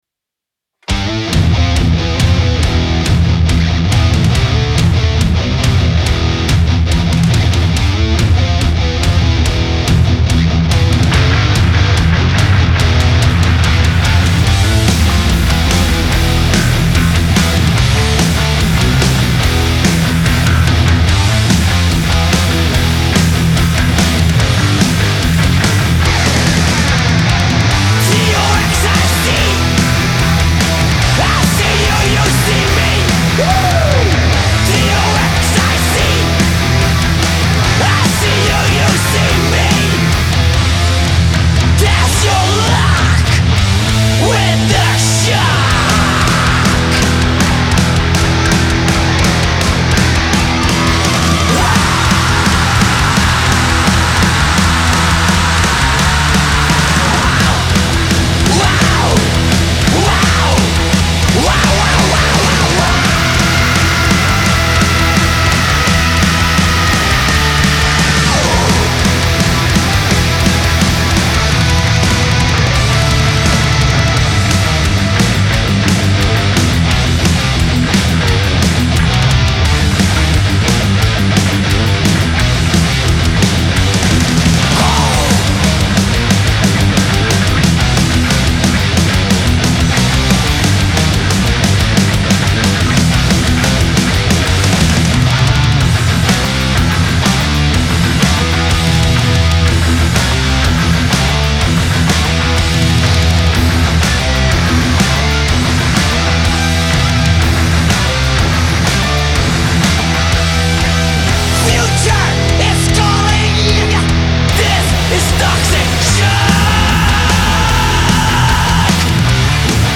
Slow Core